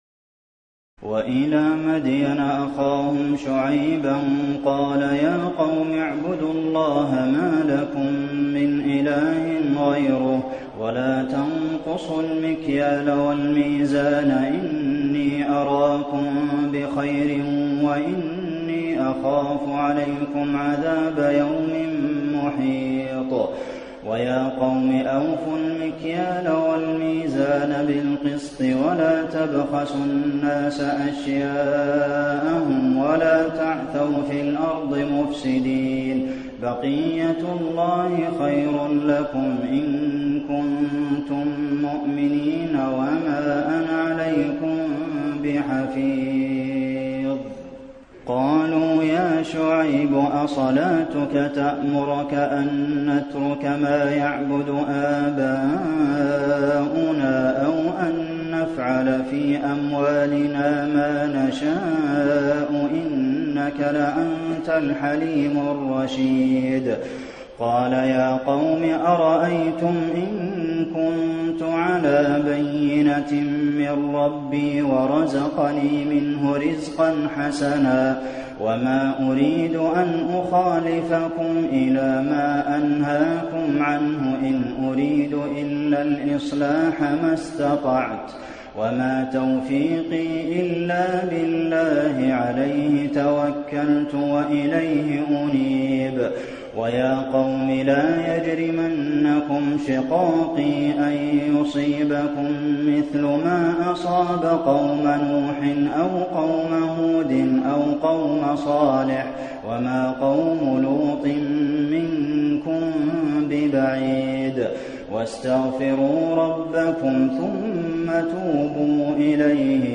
تراويح الليلة الحادية عشر رمضان 1429هـ من سورة هود (85-123) وسورة يوسف (1-29) Taraweeh 11 st night Ramadan 1429H from Surah Hud and Yusuf > تراويح الحرم النبوي عام 1429 🕌 > التراويح - تلاوات الحرمين